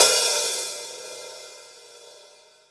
paiste hi hat2 open.wav